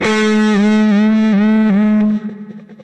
单声道失真吉他
Tag: 失真 单声道 吉他